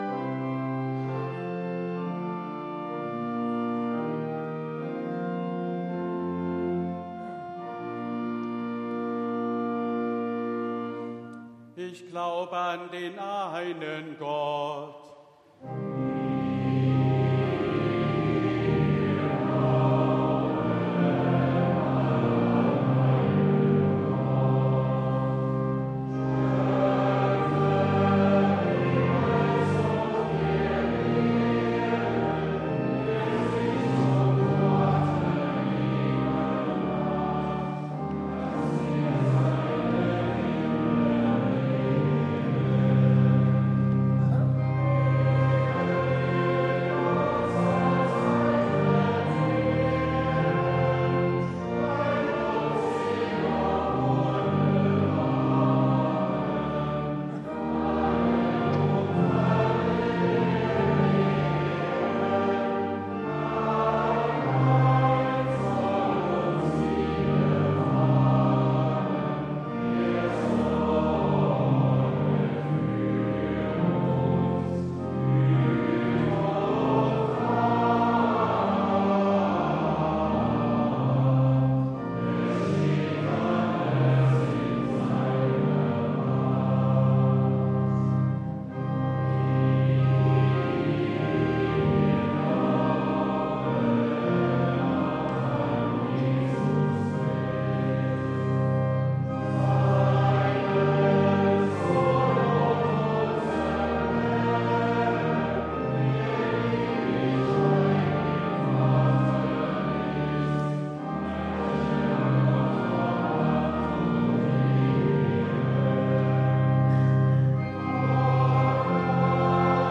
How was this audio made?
Audiomitschnitt unseres Gottesdienstes am 11.Sonntag nach Trinitatis 2024